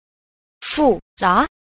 10. 複雜 – fù zá – phức tạp